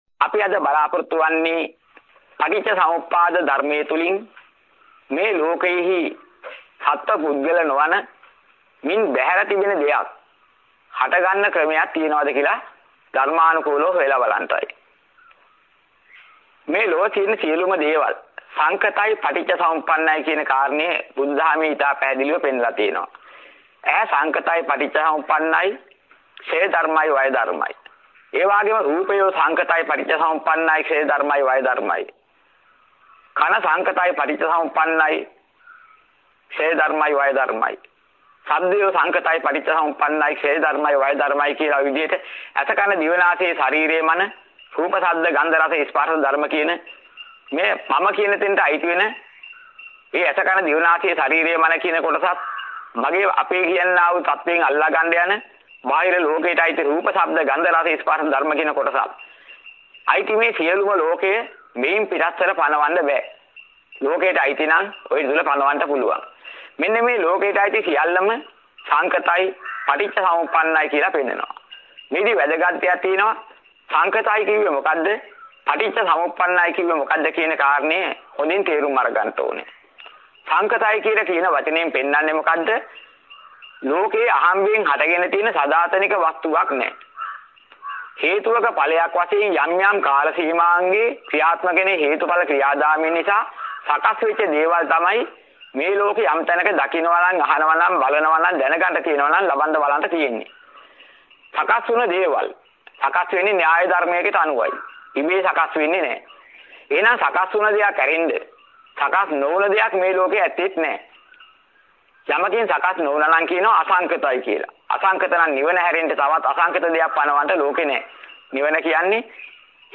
වෙනත් බ්‍රව්සරයක් භාවිතා කරන්නැයි යෝජනා කර සිටිමු 21:46 10 fast_rewind 10 fast_forward share බෙදාගන්න මෙම දේශනය පසුව සවන් දීමට අවැසි නම් මෙතැනින් බාගත කරන්න  (9 MB)